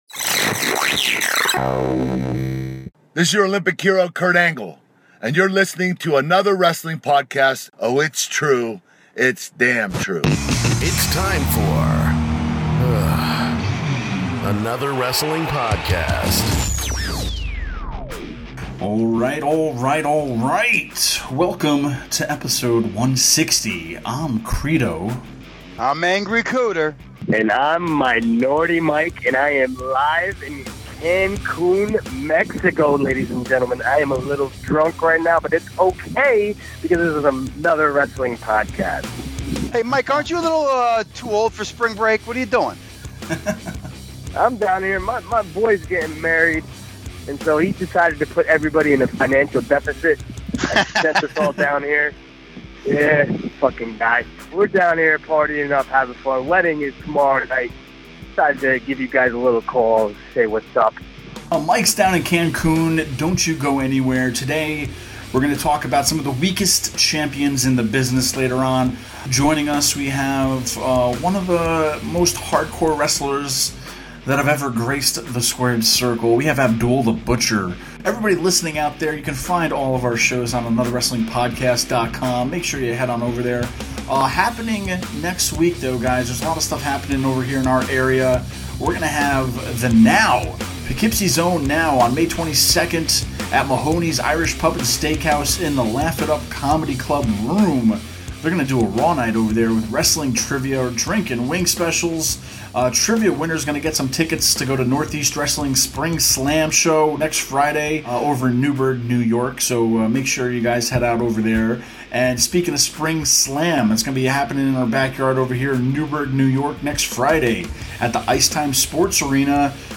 Joining us today is the madman from the Sudan Abdullah The Butcher!